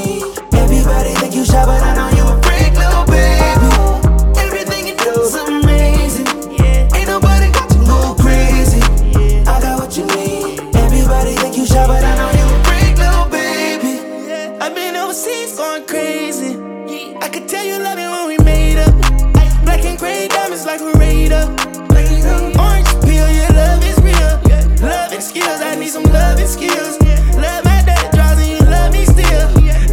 • R&B/Soul
The song is a trap-infused R&B record